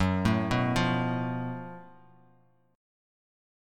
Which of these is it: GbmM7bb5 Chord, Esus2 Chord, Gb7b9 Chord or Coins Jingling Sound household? GbmM7bb5 Chord